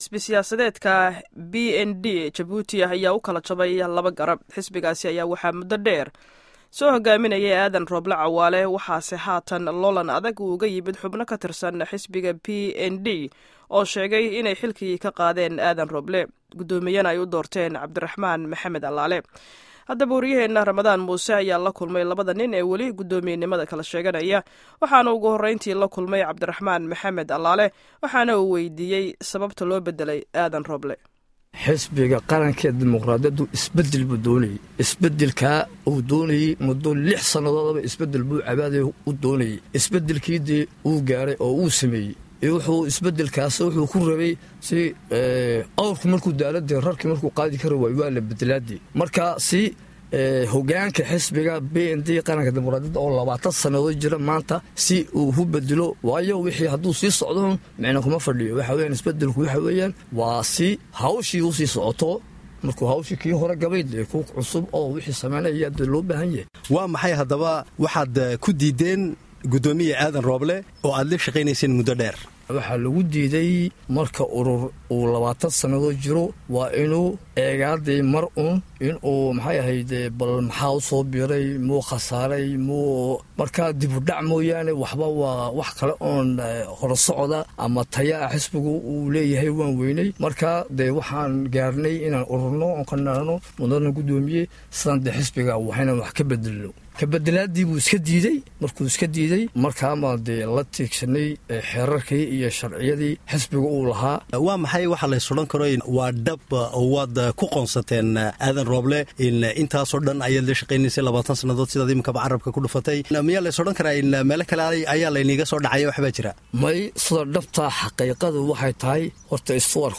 Dhageyso labada wareysi